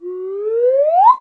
whistle.ogg